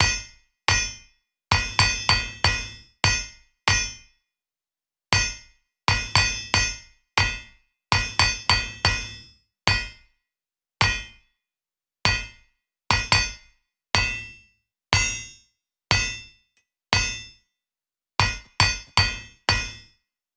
Blacksmith_Loop_01.wav